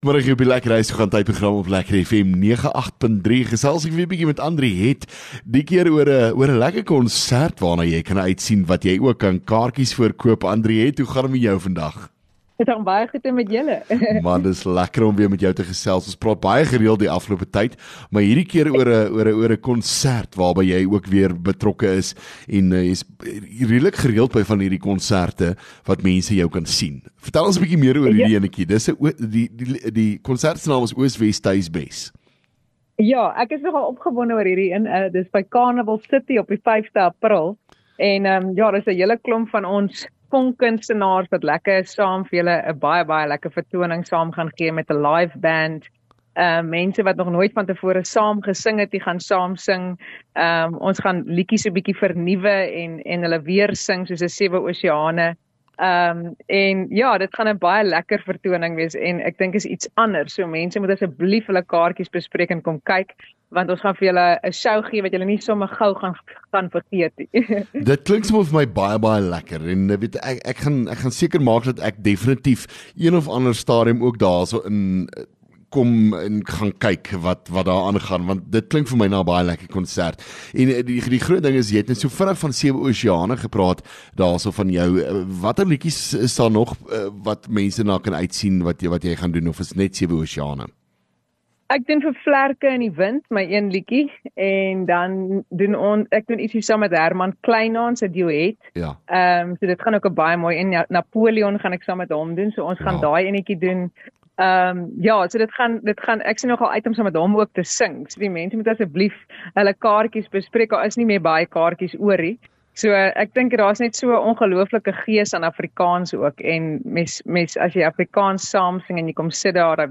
LEKKER FM | Onderhoude 26 Mar Oos WesTuis Bes